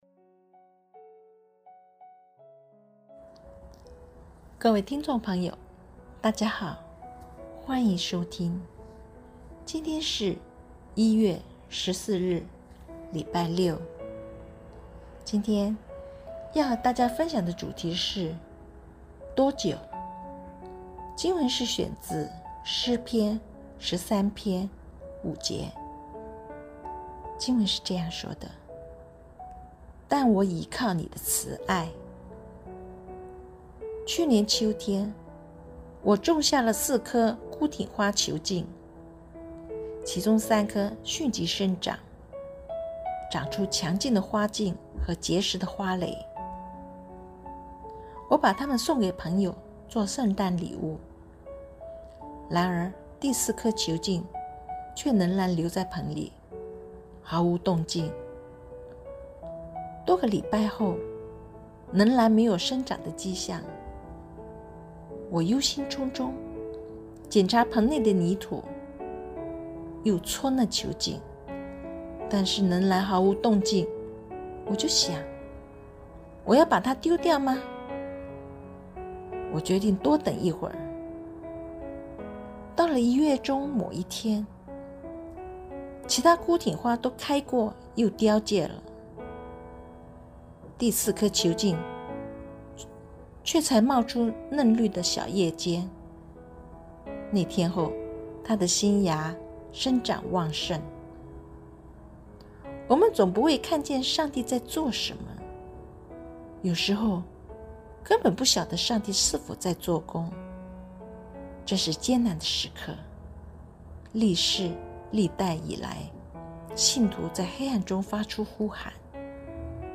錄音員